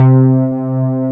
MOOG C4.wav